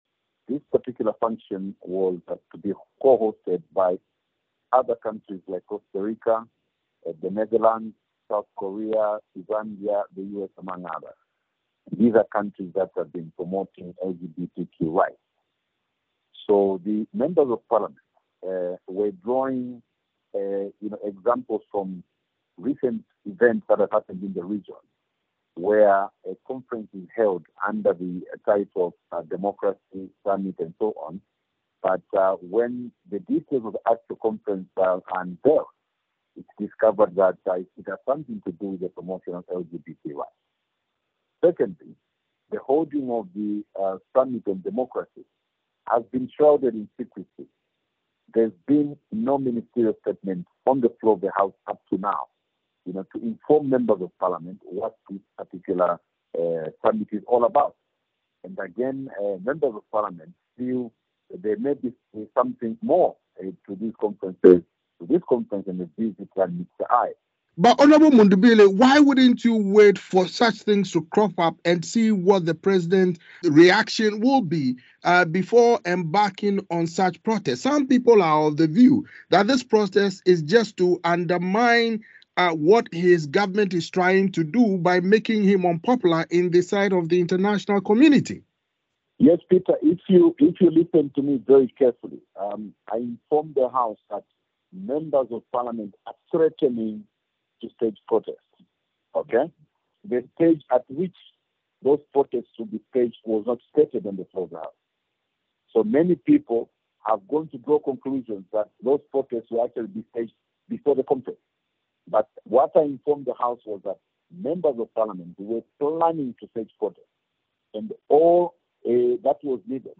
spoke to Brian Mundubile, a Zambian lawmaker.